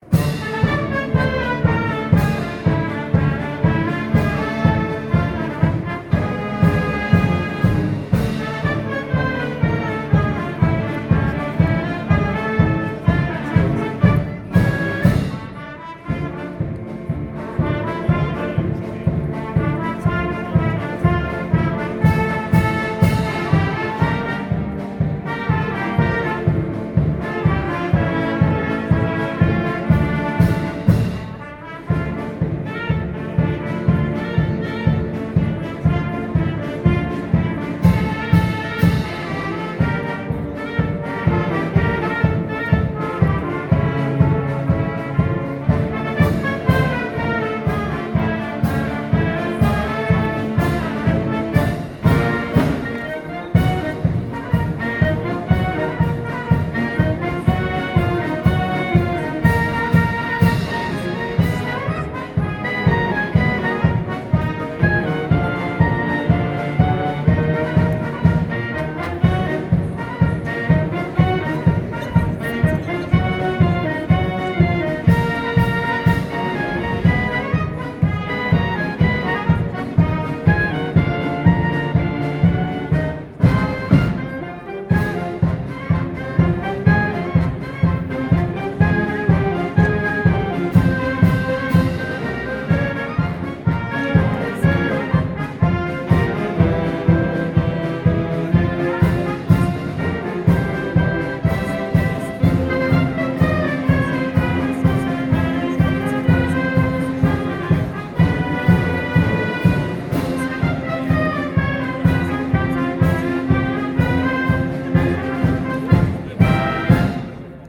Bella Ciao joué par l'Orchestre représentatif des gardes serbes.